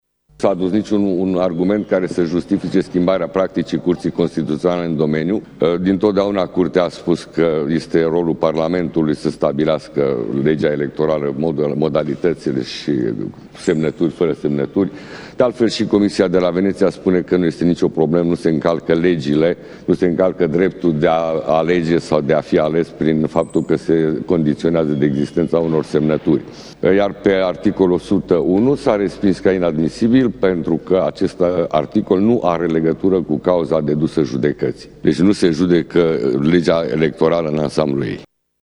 Președintele CCR, Agustin Zegrean explică motivarea Curții: